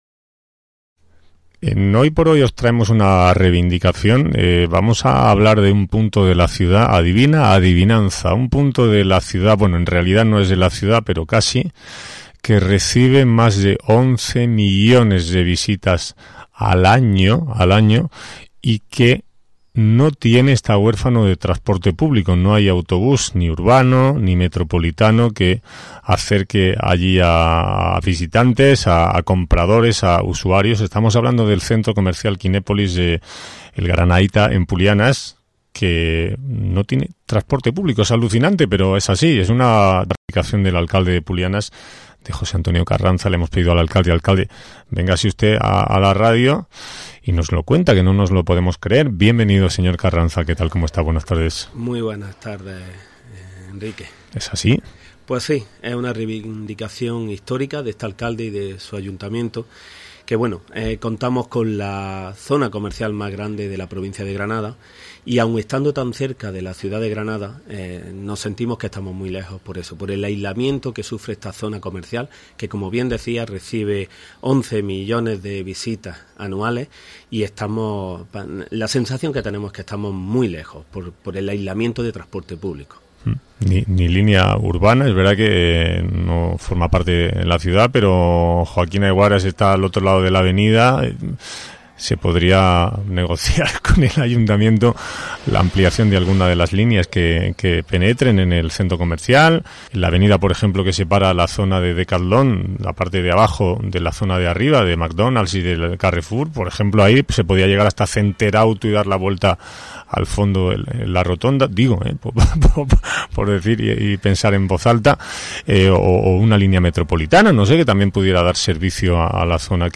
Esta mañana el Alcalde de Pulianas, José Antonio Carranza, ha sido entrevistado en Cadena SER donde ha expuesto las demandas del municipio con respecto al transporte público y ha hecho un repaso de los avances conseguidos en diferentes áreas.